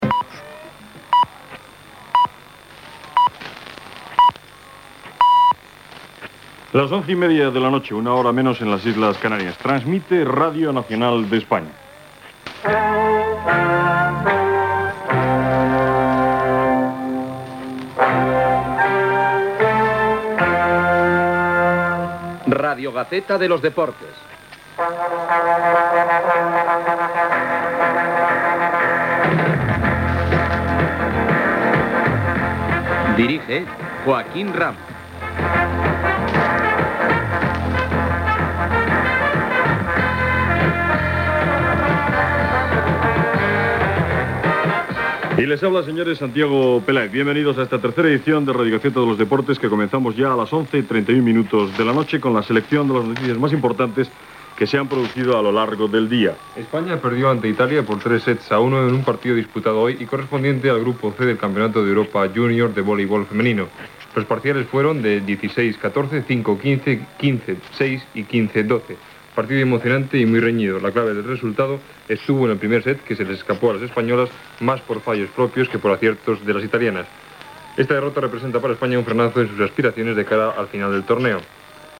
Hora, identificació i inici del programa, amb informació de voleibol femení.
Esportiu